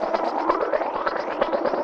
Index of /musicradar/rhythmic-inspiration-samples/130bpm
RI_ArpegiFex_130-01.wav